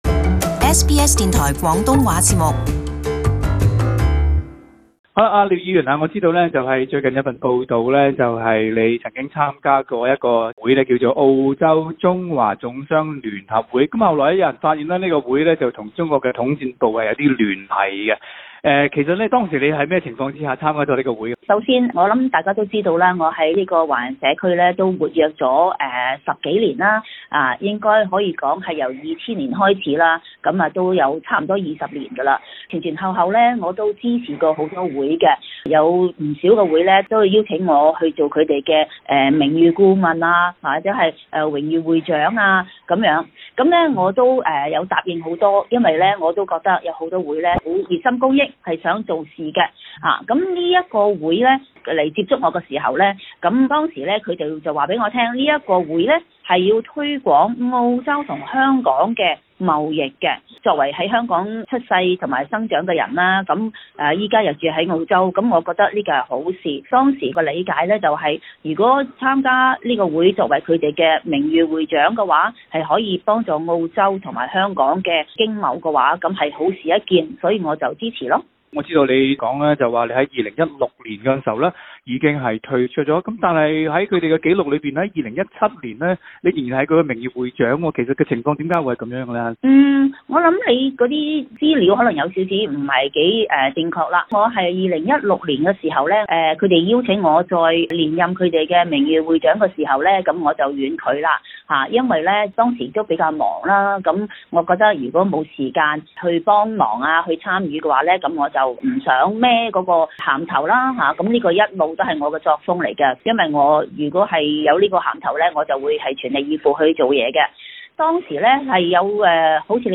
【特別專訪】廖嬋娥如何回應被指與中國有密切聯繫？